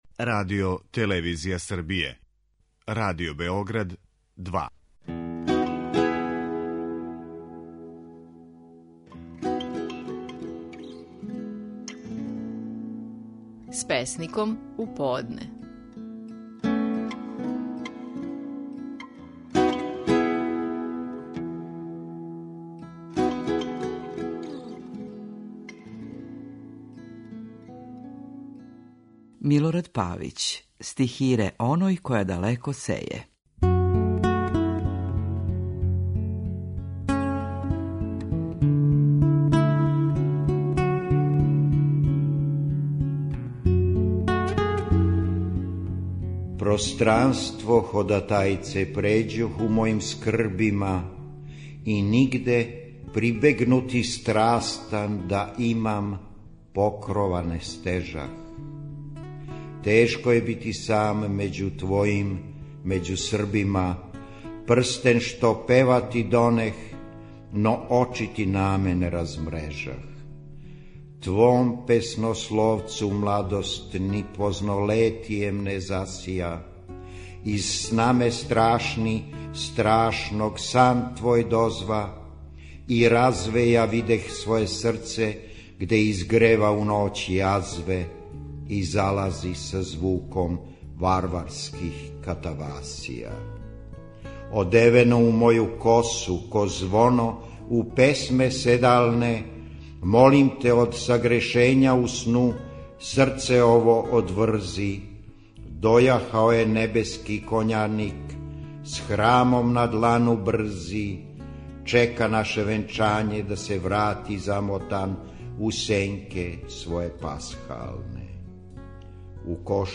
Наши најпознатији песници говоре своје стихове
У данашњој емисији слушамо како је Милорад Павић говорио стихове своје песме: „Стихире оној која далеко сеје".